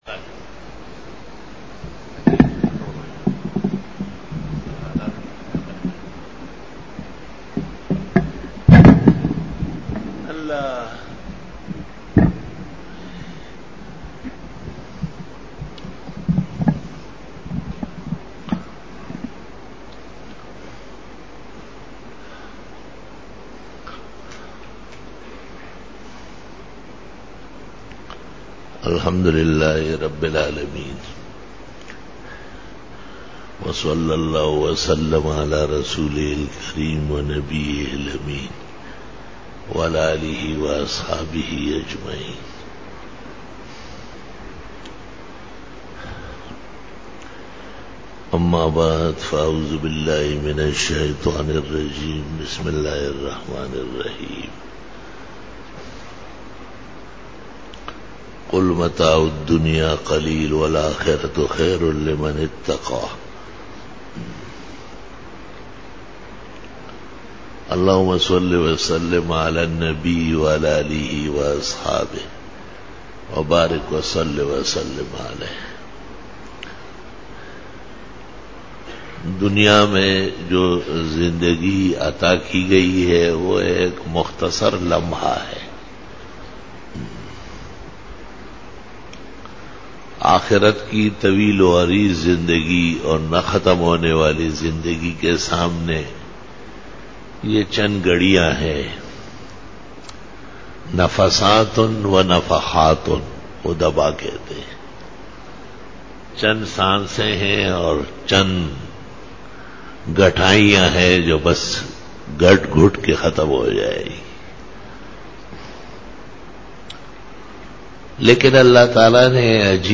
12 Bayan e juma tul mubarak 22-March-2013
Khitab-e-Jummah 2013